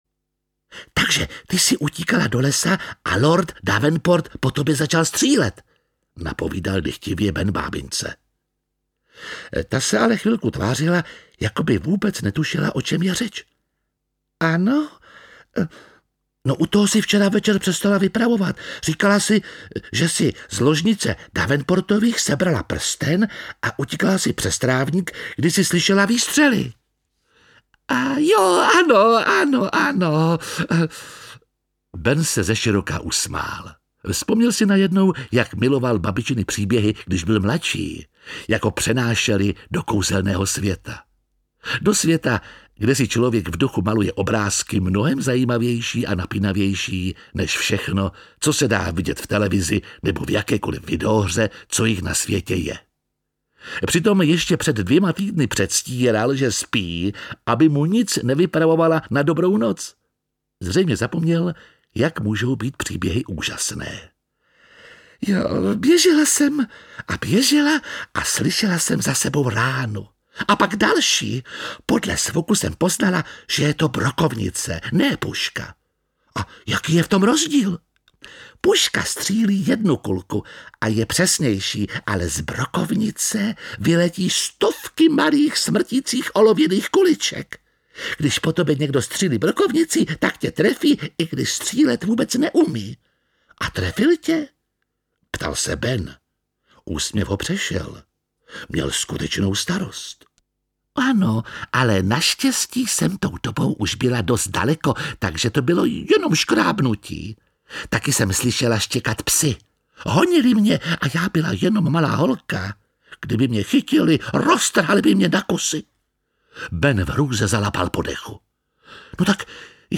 Audiobook
Read: Jiří Lábus